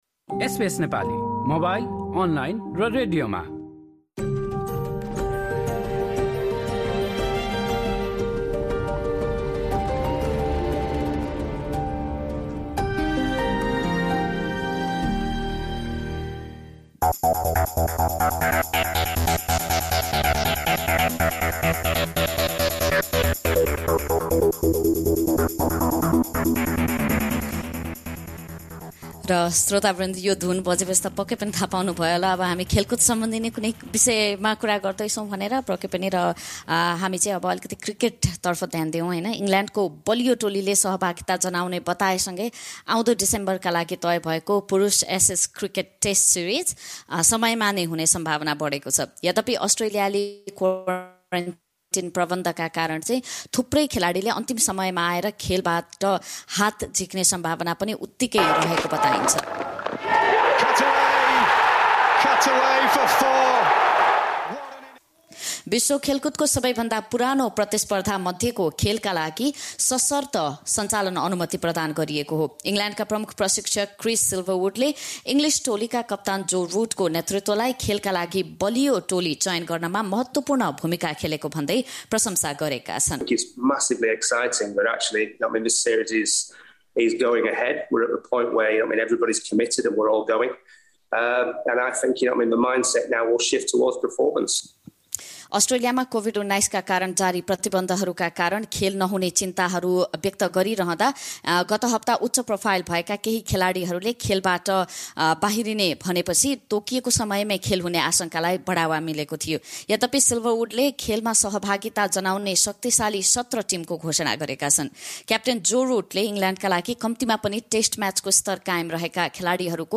विश्व खेलकुदको सबैभन्दा पुरानो प्रतिस्पर्धा मध्येको एक मानिएको अस्ट्रेलिया र इङ्गल्यान्ड बिचको एसेज शृङ्खला आउँदो डिसेम्बरदेखि सुरु हुँदैछ। यस बारे एक रिपोर्ट